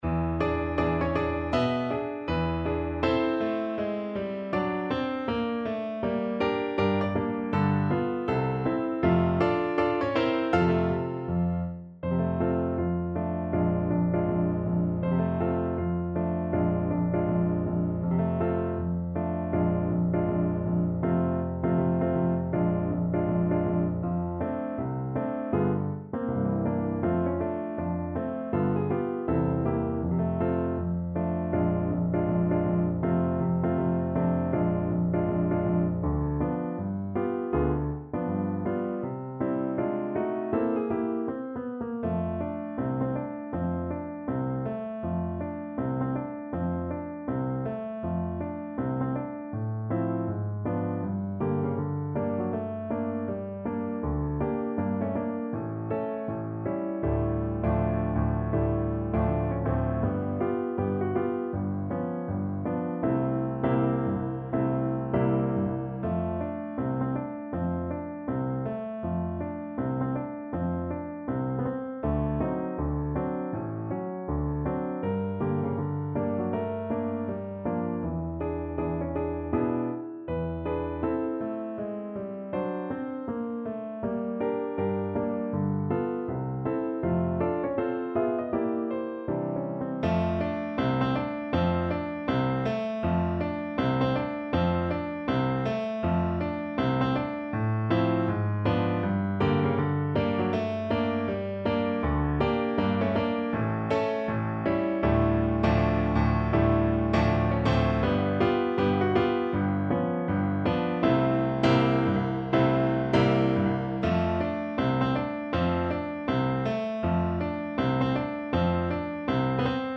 ~ = 160 Moderato